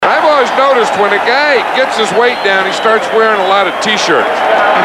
Category: Radio   Right: Personal
Tags: sports radio